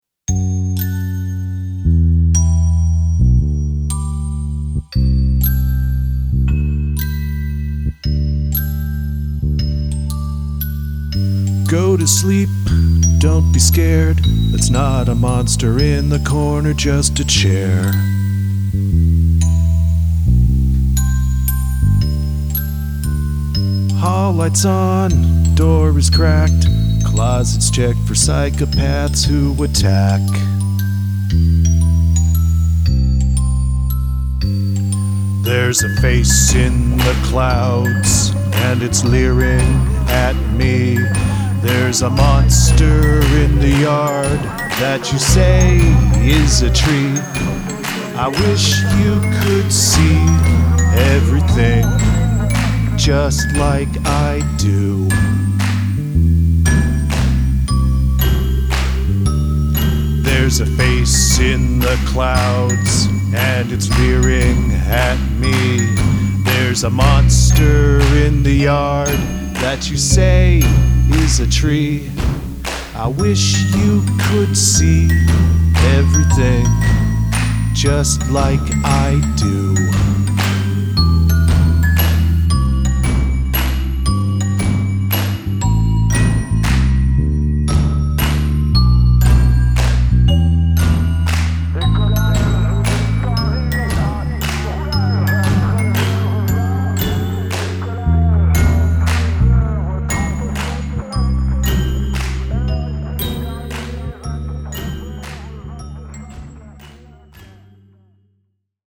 Use of field recording
(yes, that's another numbers station in there)